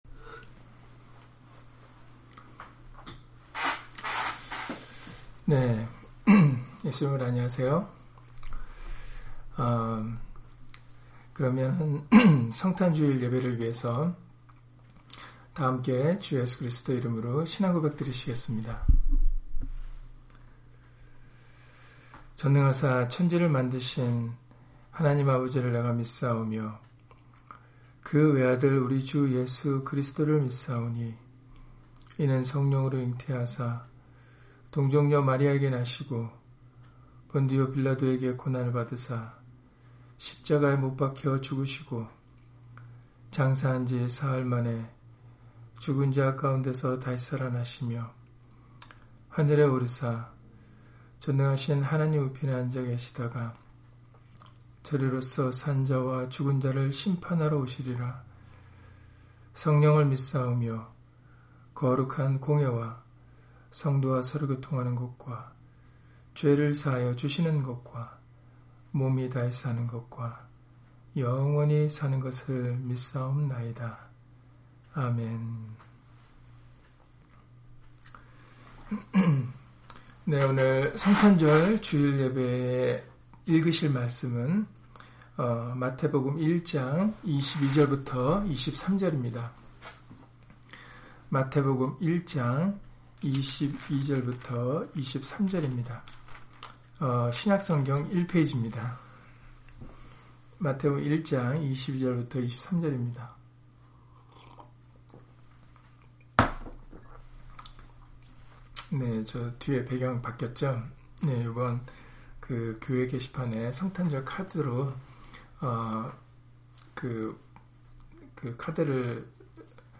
마태복음 1장 22-23절 [성탄 주일] - 주일/수요예배 설교 - 주 예수 그리스도 이름 예배당